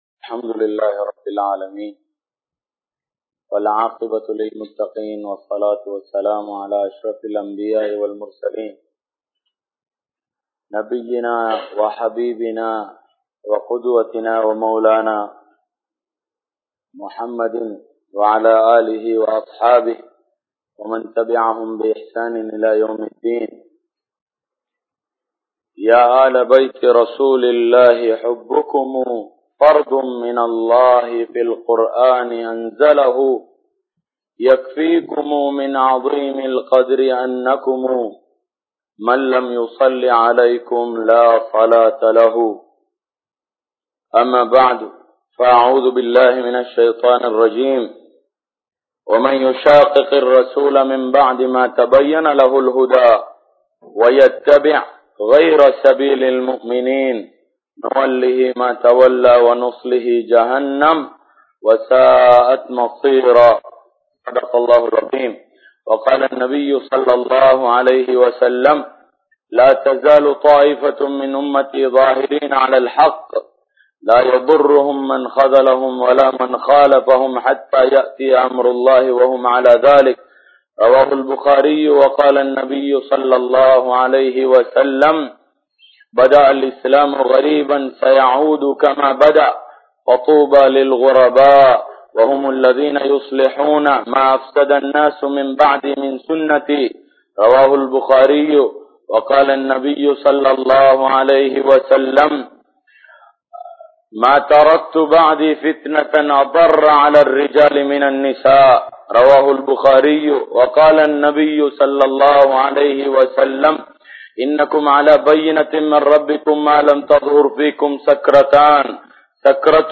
Hijab Thelivu Maanaadu(03) (ஹிஜாப் தெளிவு மாநாடு (03)) | Audio Bayans | All Ceylon Muslim Youth Community | Addalaichenai